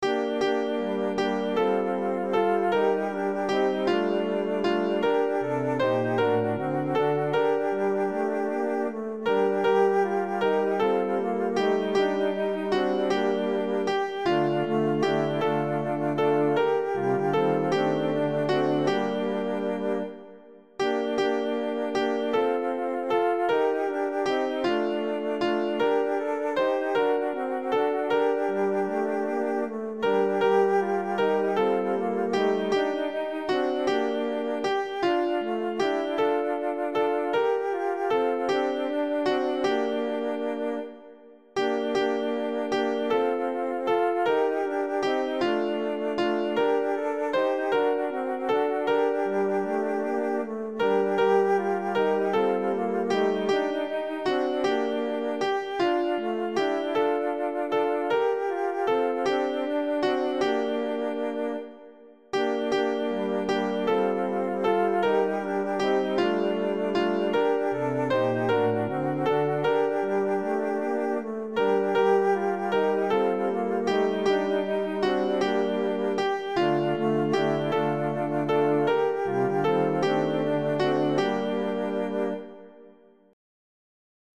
soprane
A-Bethleem-Jesus-est-ne-soprane.mp3